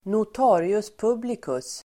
Uttal: [not'a:rius p'ub:likus]